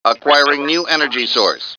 mission_voice_idcm012.wav